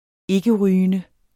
Udtale [ -ˌʁyːənə ]